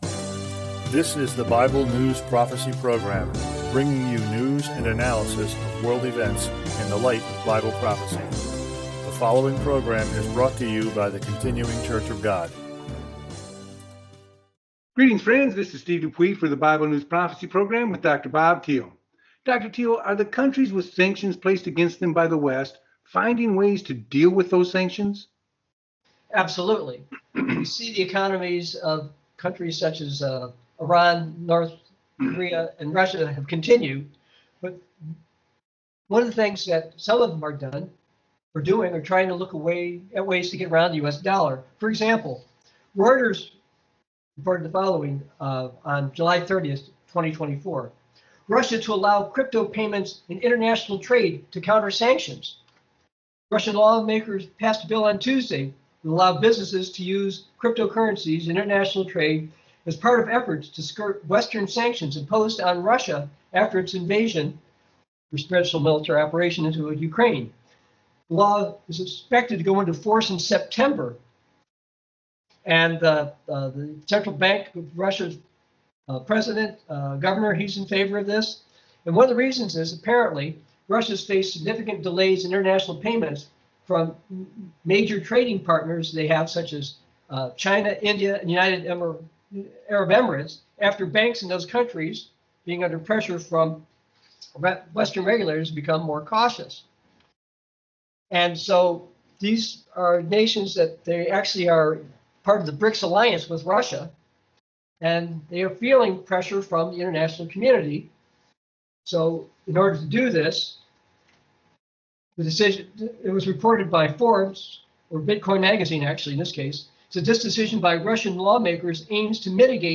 Talk Show Episode, Audio Podcast, Bible News Prophecy and Russia, USA, and Bitcoin on , show guests , about Russia USA and Bitcoin, categorized as Health & Lifestyle,History,Relationship Counseling,Philosophy,Psychology,Christianity,Inspirational,Motivational,Society and Culture